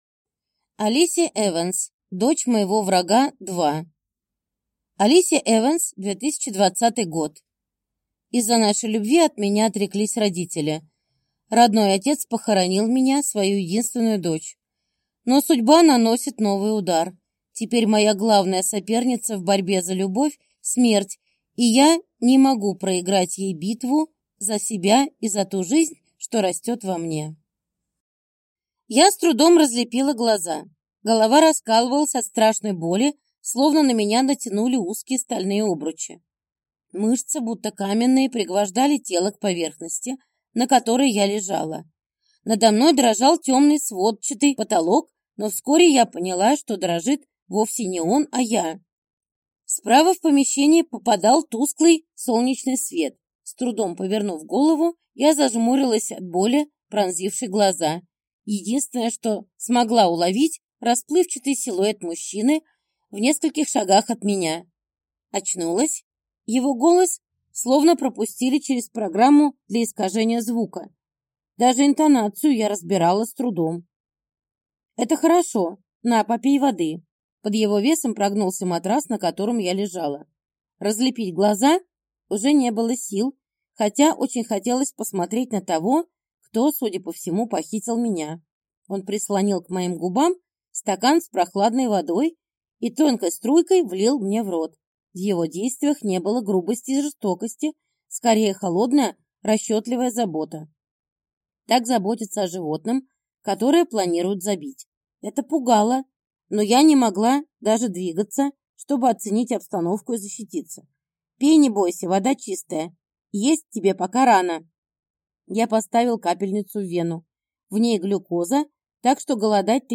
Аудиокнига Дочь моего врага – 2 | Библиотека аудиокниг